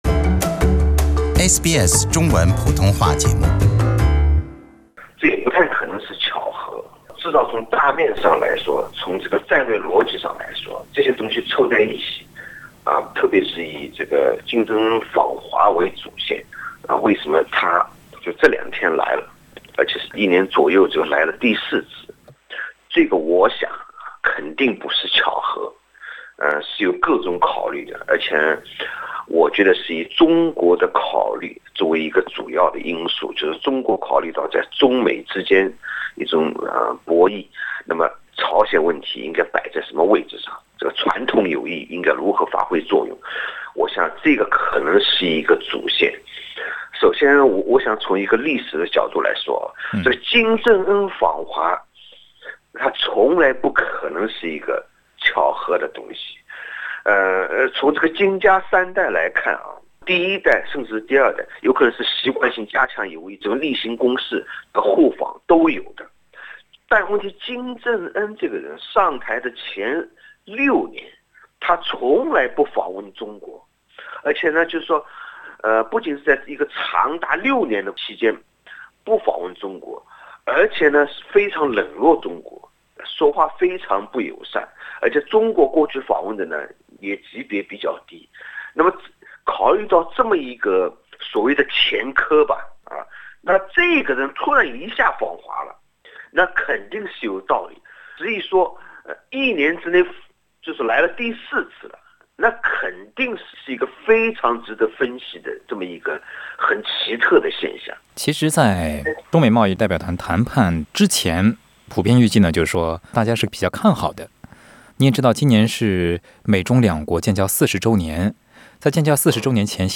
Source: AAP SBS 普通话电台 View Podcast Series Follow and Subscribe Apple Podcasts YouTube Spotify Download (23.26MB) Download the SBS Audio app Available on iOS and Android 朝鲜领导人金正恩在一年内第四次访问中国。